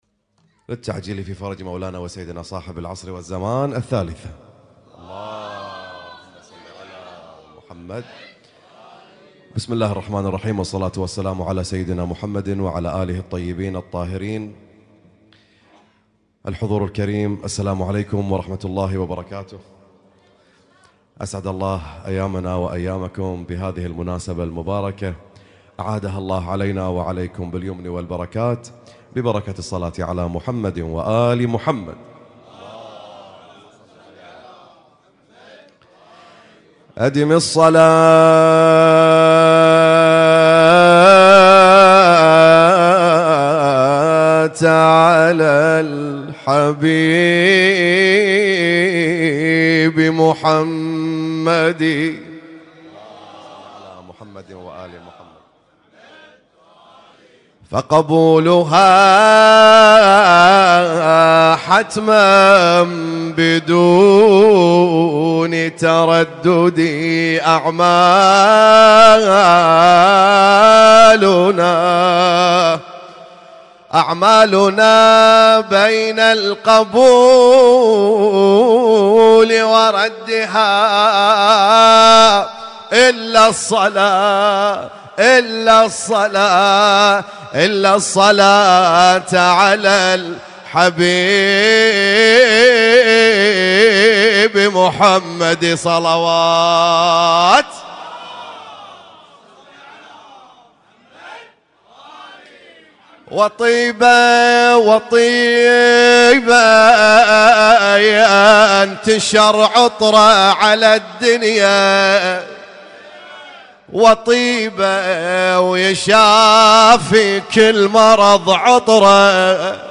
اسم النشيد:: مولد الرسول الأعظم والإمام الصادق عليهما السلام